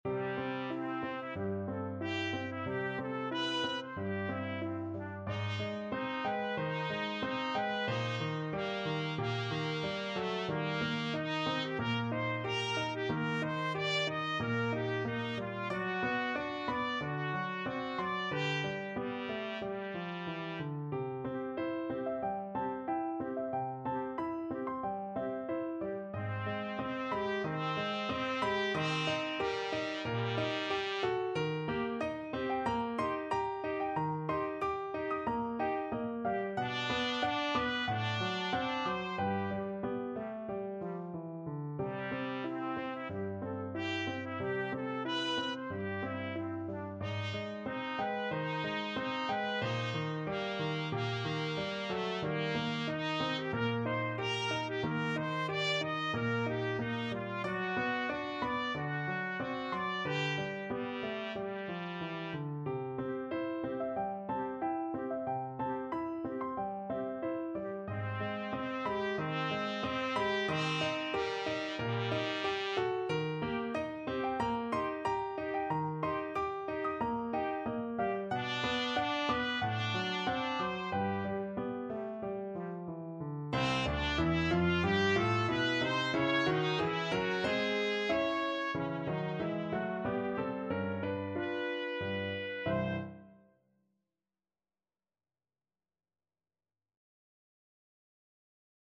Trumpet
C major (Sounding Pitch) D major (Trumpet in Bb) (View more C major Music for Trumpet )
4/4 (View more 4/4 Music)
G4-Eb6
Andantino =92 (View more music marked Andantino)
Classical (View more Classical Trumpet Music)